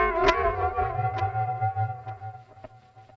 03 Stranguato Bmin.wav